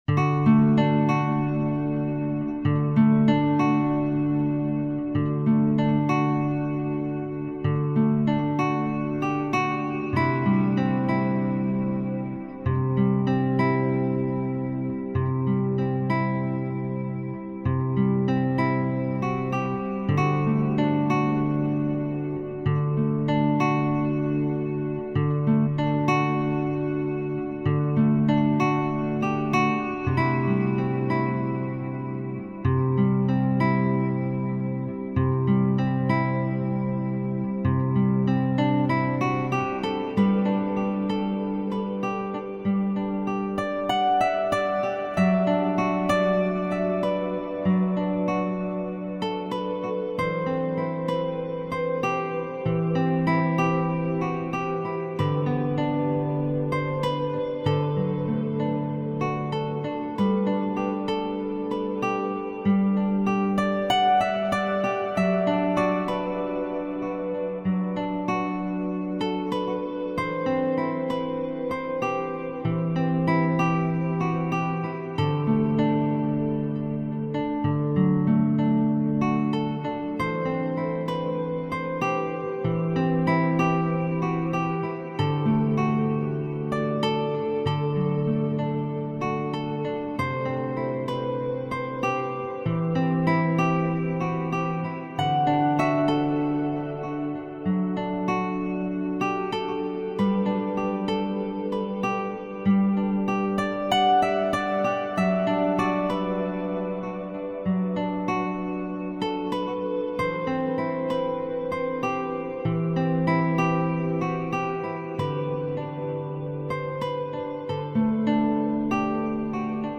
Soundtrack with an African Feel!
Tribal drums, moving panoramic